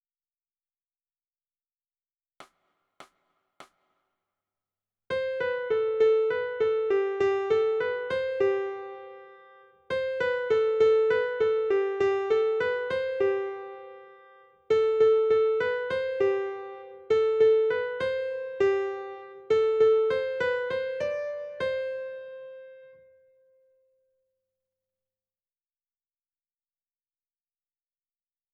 ※曲の中には、無音部分が入っていることもあります。